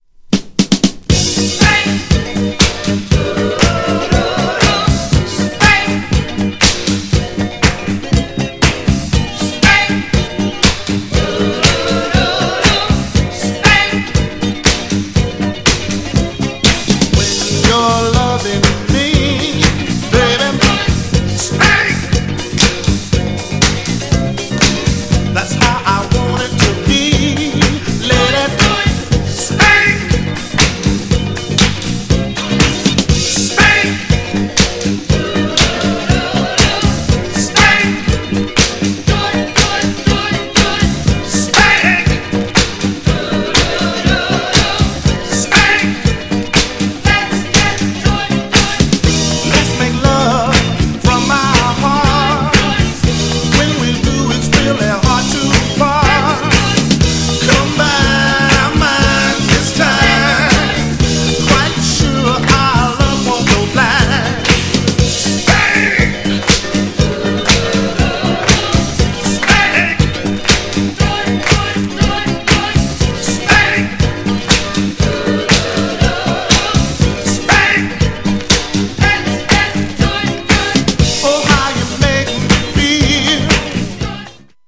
エレクトリックなアレンジになっていますがオリジナルのGROOVE感はそのまま!!
GENRE Dance Classic
BPM 116〜120BPM
GARAGE_CLASSIC # GROOVY
エレクトロ
パーカッシブ # ラテン